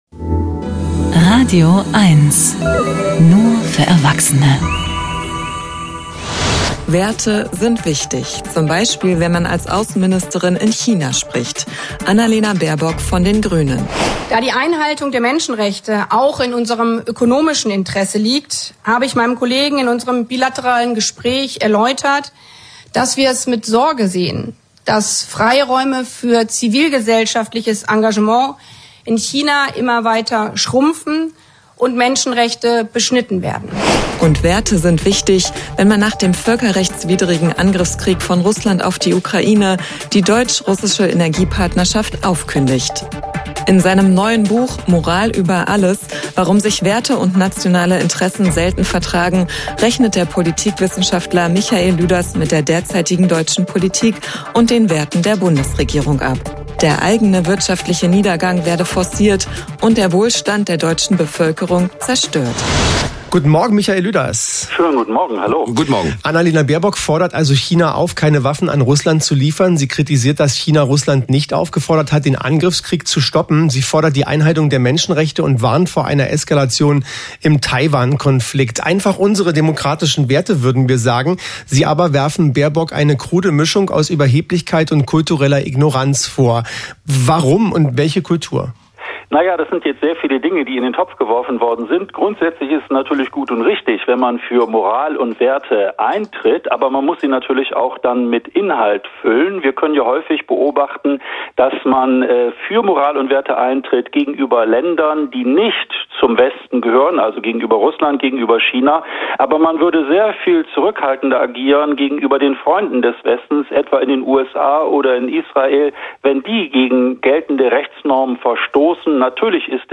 bzw. hier das Kurznterview als Audio: